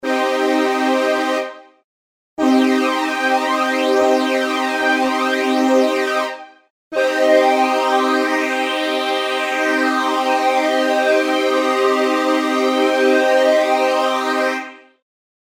Flanging_effect.mp3